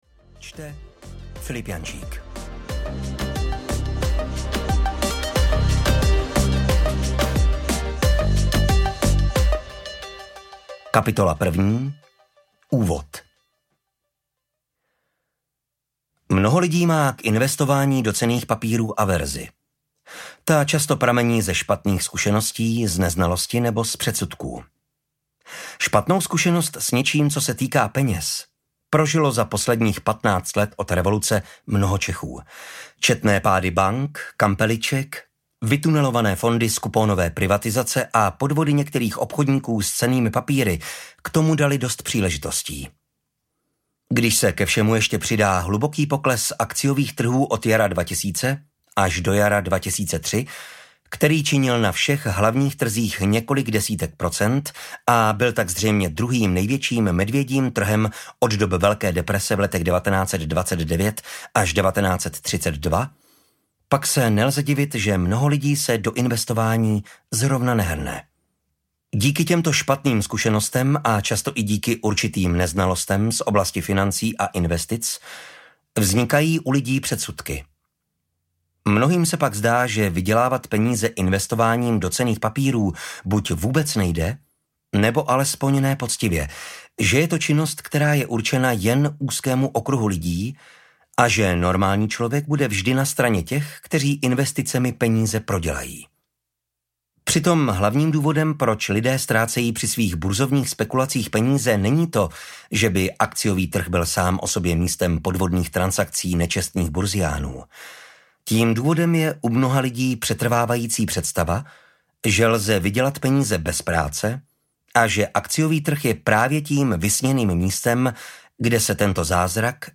Naučte se investovat audiokniha
Ukázka z knihy
naucte-se-investovat-audiokniha